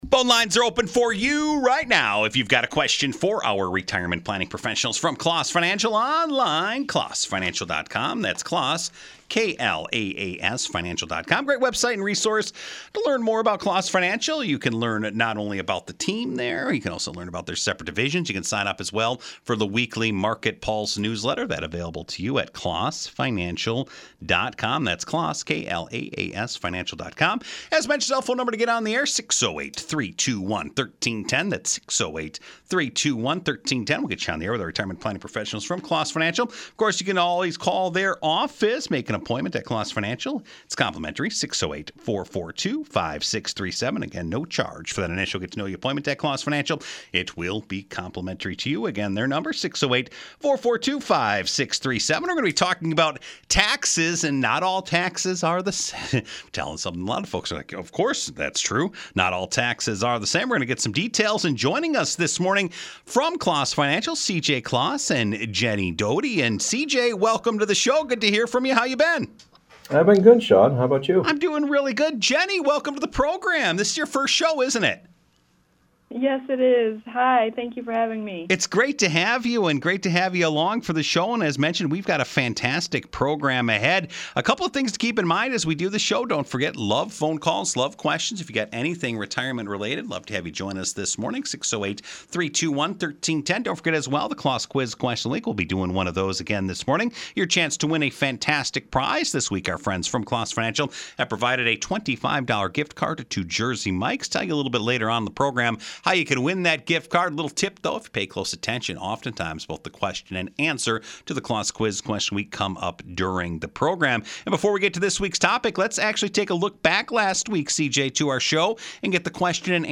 Answers to all your retirement questions in one place. Once a week we take calls and talk about getting your financial house in order.
Shows are broadcast live every Thursday from 8:05-8:35 am on “Madison in the Morning” on News/Talk 1310 WIBA AM in Madison, Wisconsin.